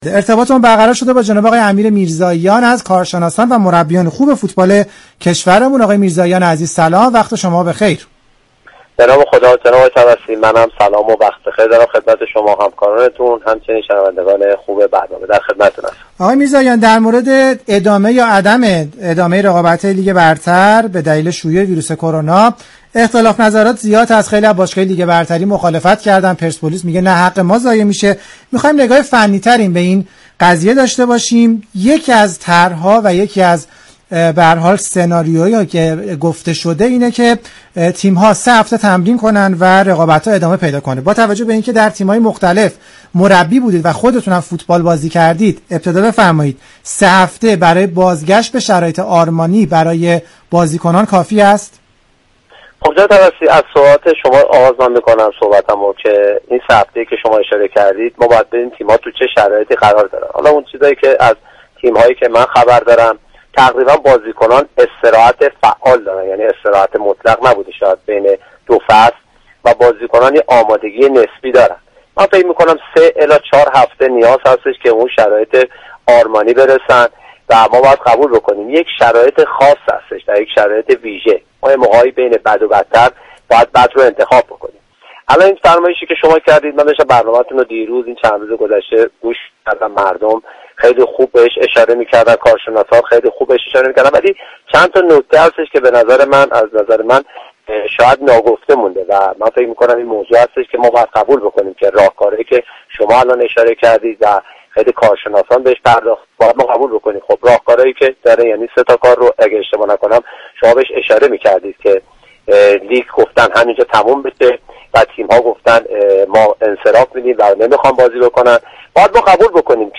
برنامه زنده "از فوتبال چه خبر؟"